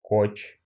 The koch (Russian: коч, IPA: [ˈkotɕ]